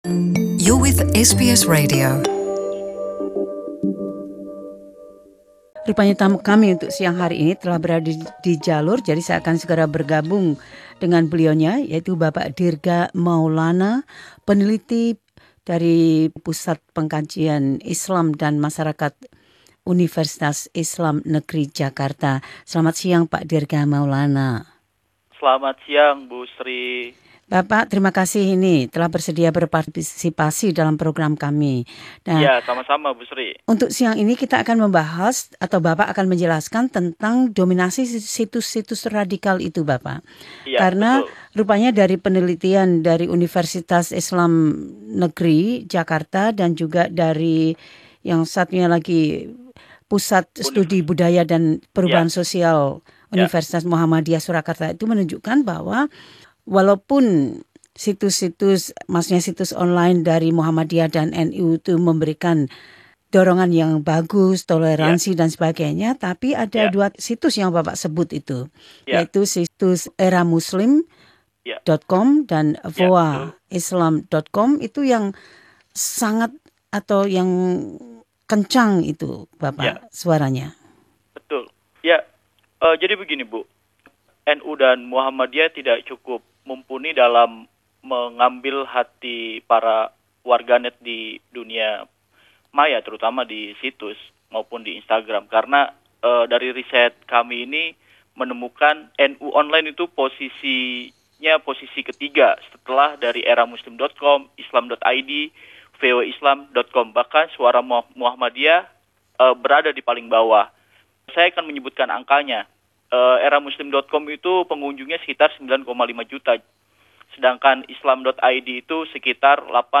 talking on the phone to SBS Indonesian